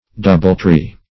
Doubletree \Dou"ble*tree`\, n.